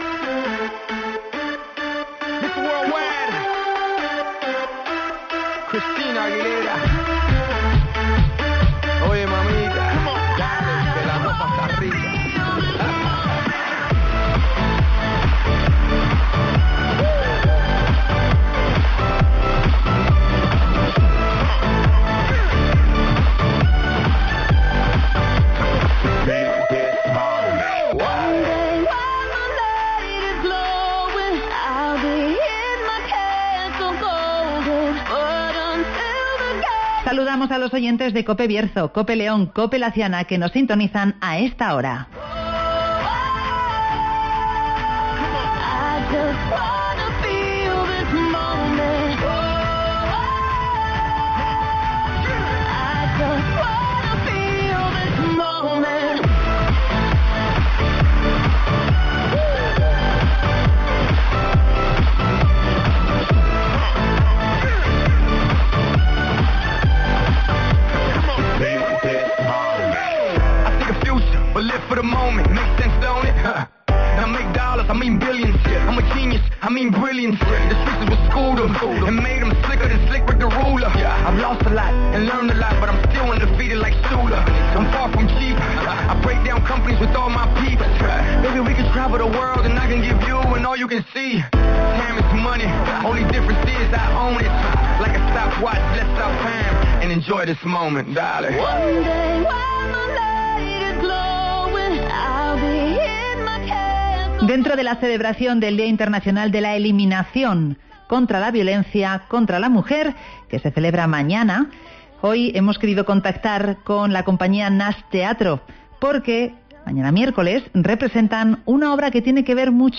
‘Cinco horas con Mariana’ llega a Bembibre (Entrevista